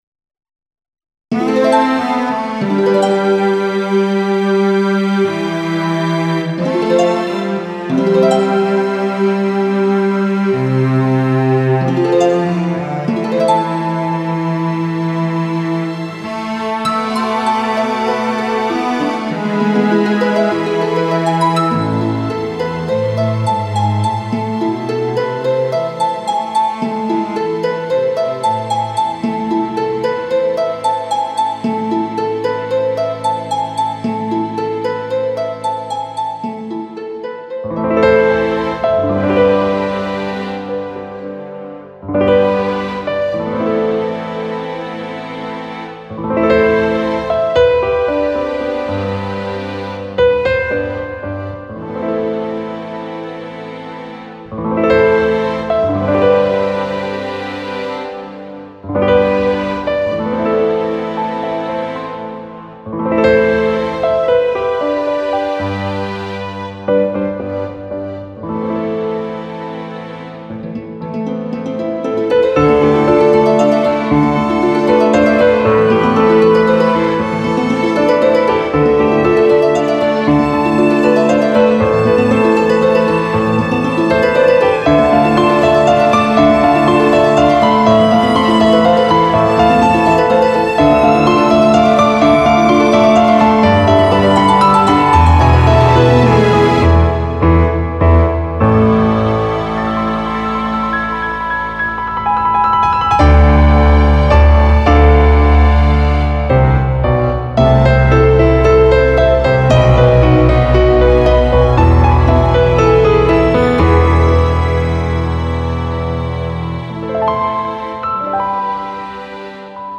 この曲はファンタジー世界を題材にしたメドレーっぽい曲で、ピアノ、ストリングスが中心になっているインスト曲です。